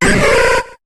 Cri d'Embrylex dans Pokémon HOME.